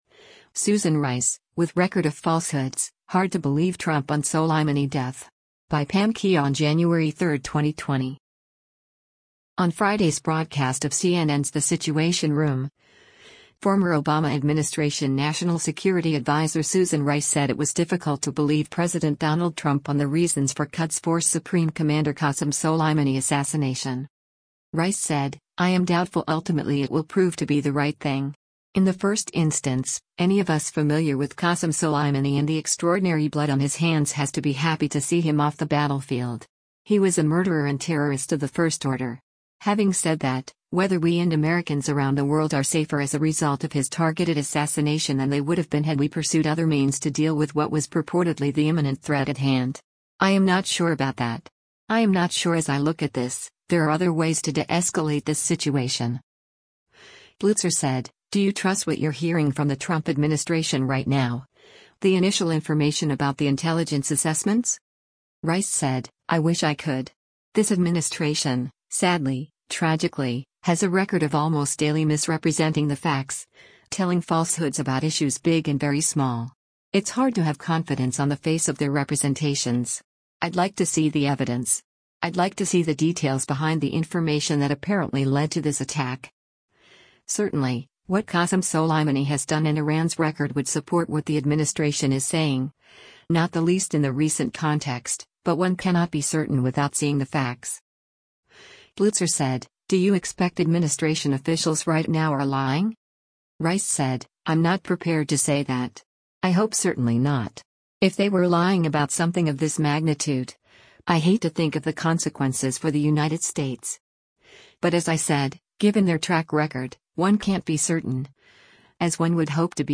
On Friday’s broadcast of CNN’s “The Situation Room,” former Obama administration National Security Advisor Susan Rice said it was difficult to believe President Donald Trump on the reasons for Quds Force Supreme Commander Qasem Soleimani assassination.